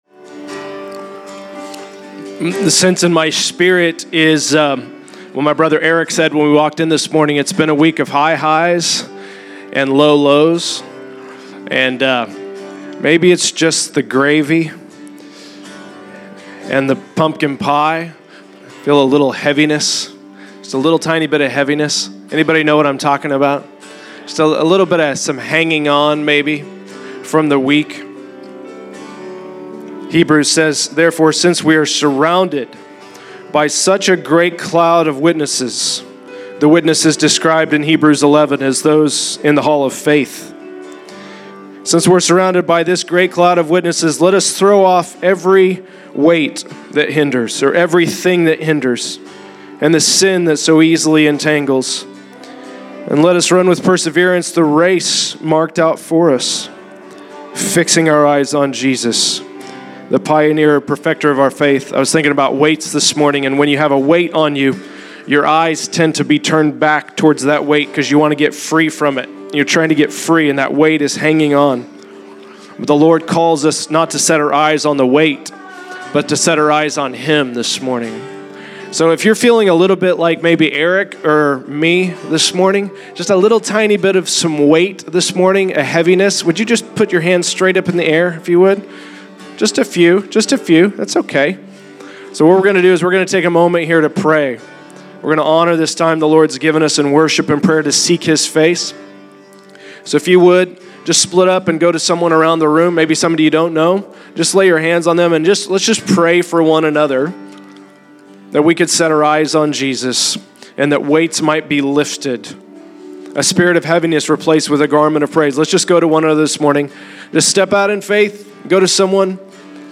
Heaviness Lifted - A Word During Worship
A word out of Hebrews 12 came during worship and led into a time of ministry over one another for heaviness to lift and eyes to be set on Jesus.